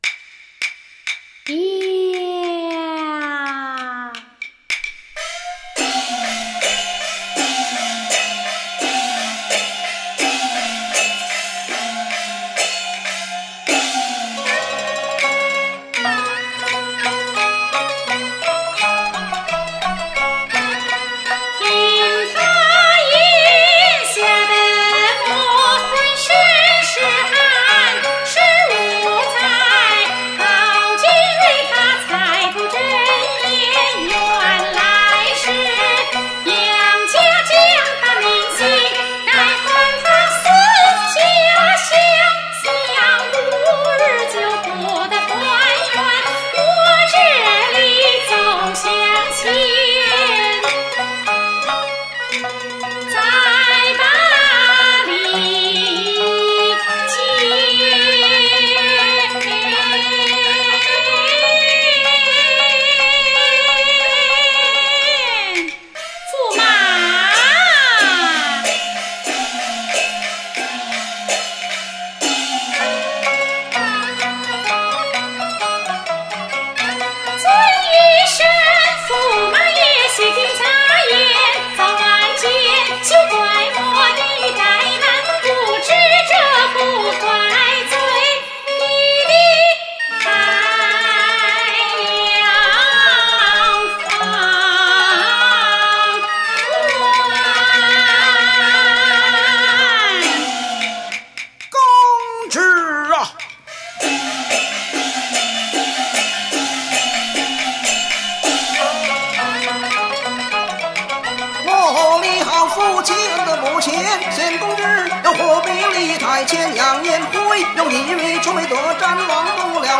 此剧是生、旦唱腔成就较高的传统戏之一，《坐宫》就是其中一折代表作。